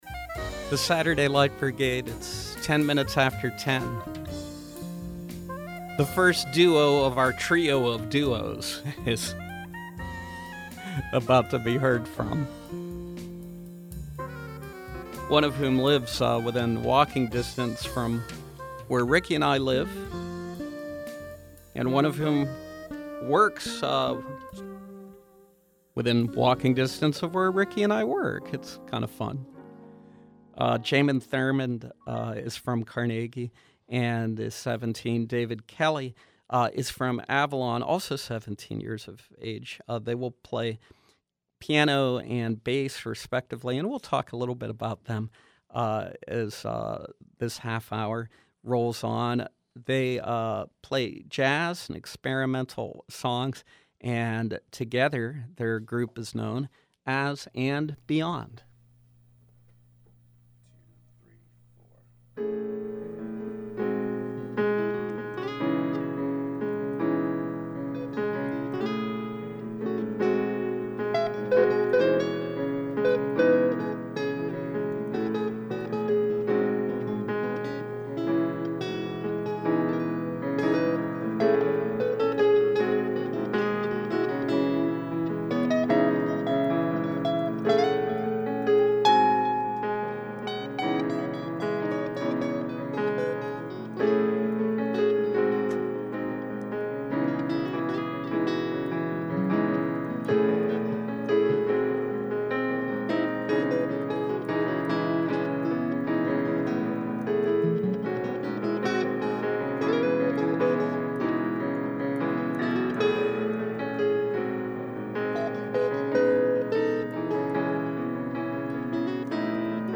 Piano and Bass duo
performing original jazz and experimental songs, live on SLB.